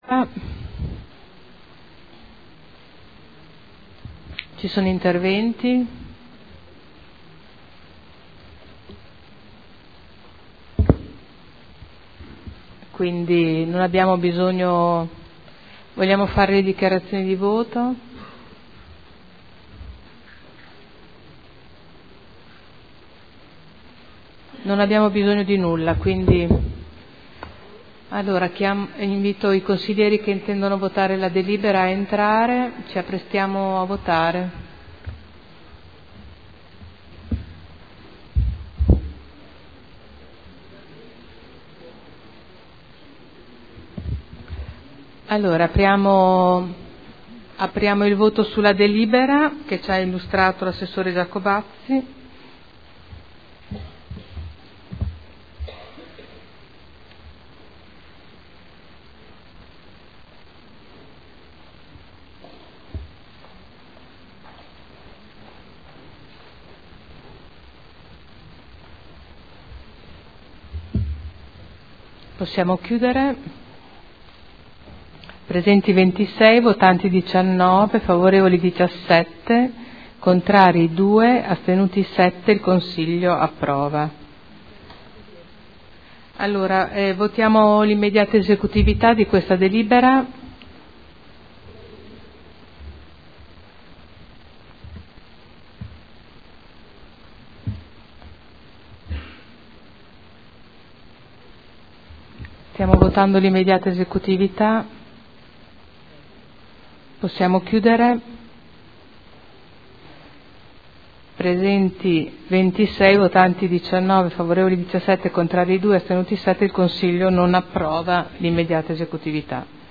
Seduta del 31 marzo.